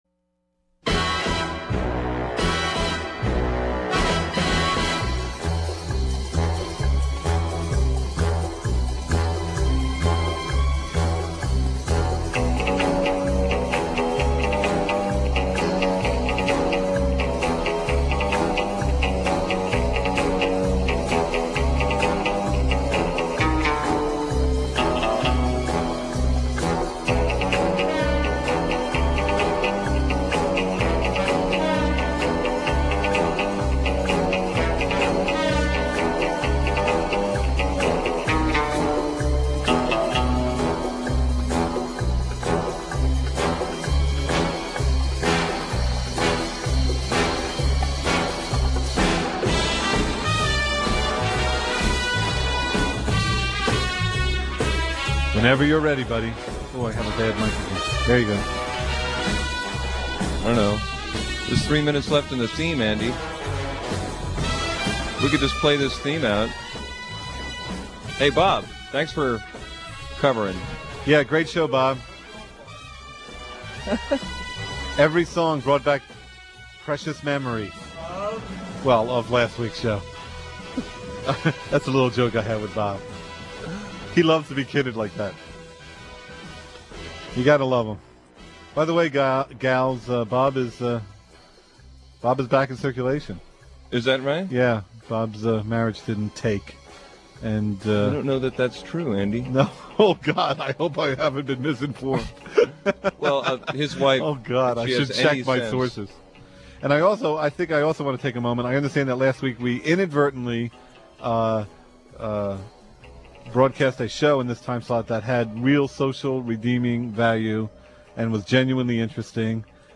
listeners sing 100 Bottles of Beer (from 1998-ish) from Aug 5, 2015